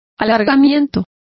Complete with pronunciation of the translation of stretches.